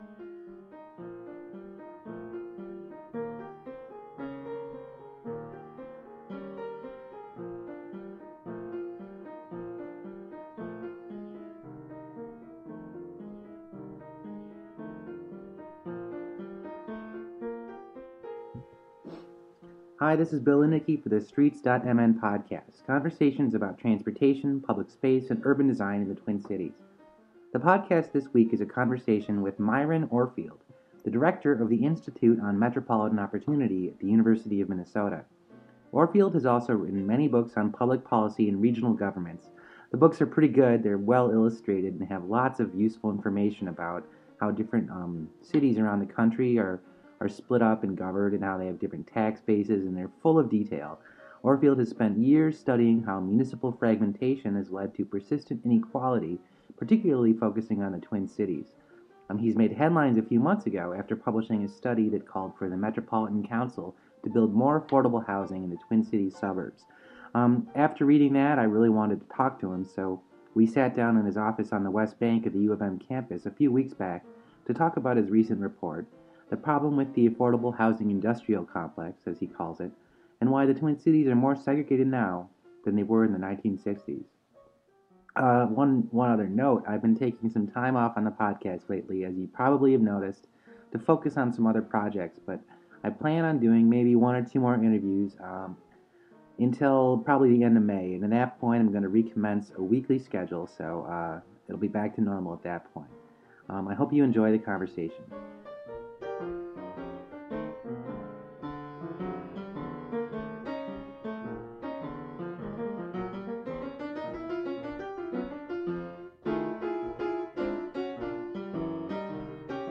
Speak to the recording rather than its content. We sat down in his office on the West Bank of the U of M campus a few weeks back to talk about his recent report , the problem with the affordable housing industrial complex, and why the Twin Cities are more segregated now than they were in the 1960s.